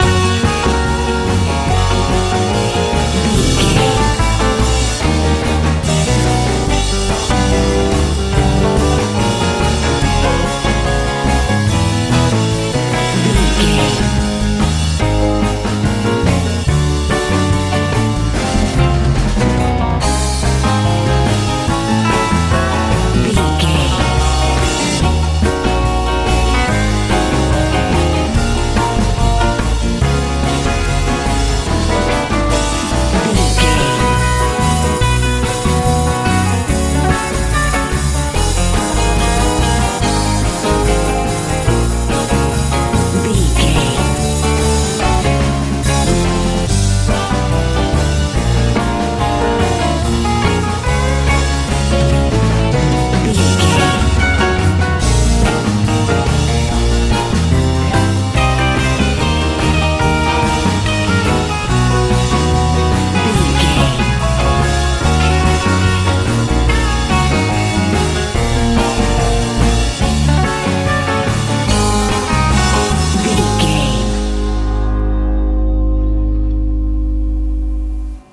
surf rock
Ionian/Major
F♯
Fast
fun
playful
electric guitar
piano
bass guitar
drums
90s
2000s